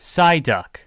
Voiced byMichael Haigney (English, 1998-2006)
Rikako Aikawa (Japanese)
Psyduck_Voice_Line.ogg.mp3